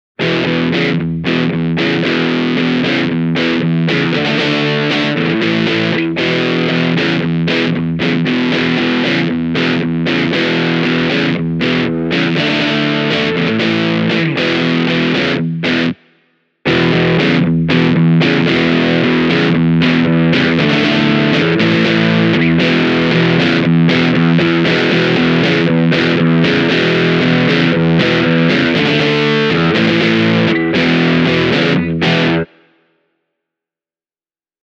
This short audio clip gives you an idea of the Fried Eye’s basic sound with the Gain control set to 12 o’clock. The first half showcases the distortion side on its own, with the boost kicking in for the second half. I used a Hamer USA Studio Custom with the bridge humbucker engaged. The clip was recorded direct off a Blackstar HT-1R’s speaker emulated output:
bluetone-fried-eye-distortion-boost-e28093-basic-sound.mp3